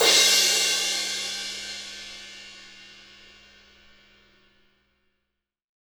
Index of /kb6/Akai_MPC500/1. Kits/Amb Rm Kit